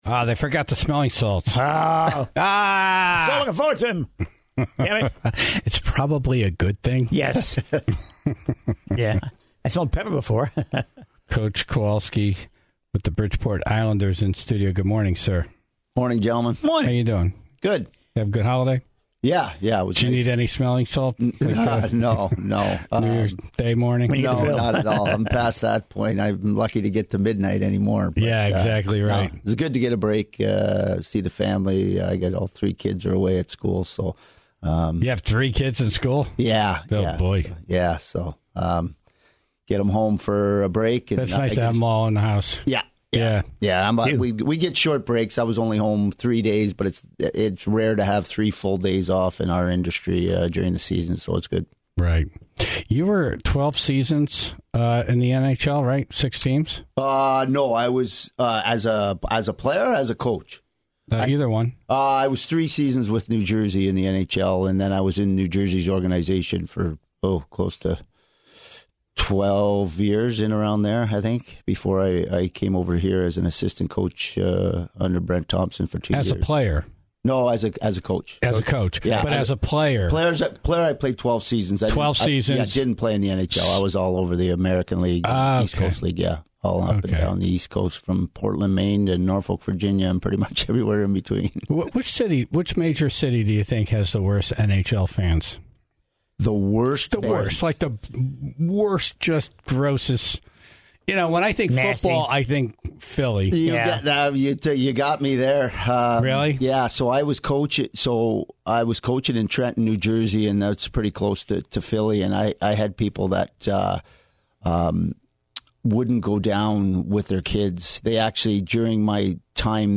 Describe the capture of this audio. and the Tribe called in what they know about the military officers that are asked to stand at ceremony for long periods of time without moving.